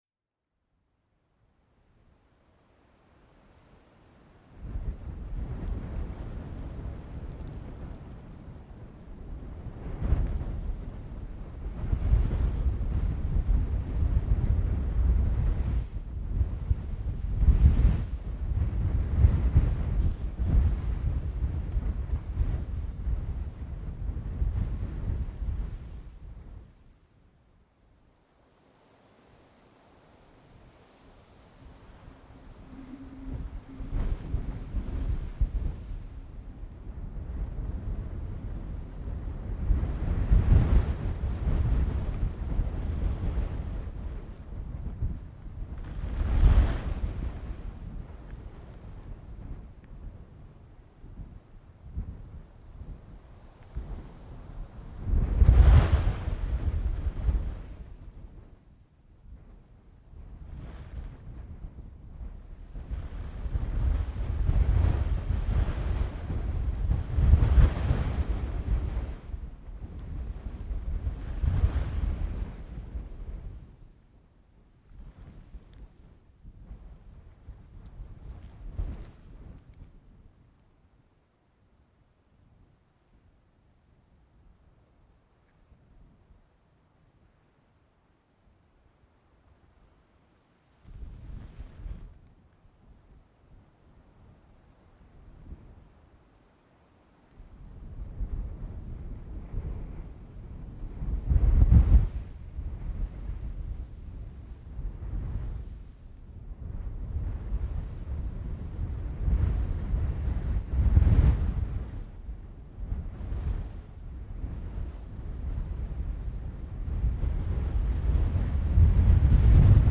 На этой странице собраны разнообразные звуки песка: от шуршания под ногами до шелеста дюн на ветру.
Звуки пустыни в разгар песчаной бури